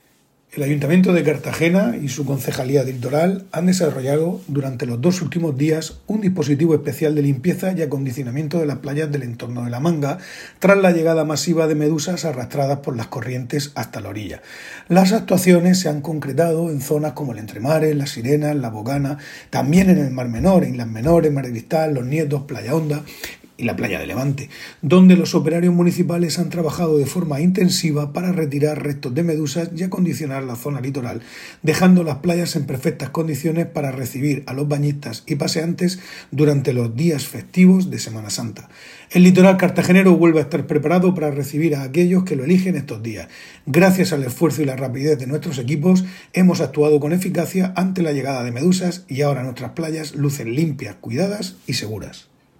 Enlace a Declaraciones de Gonzalo López sobre limpieza en playas en Semana Santa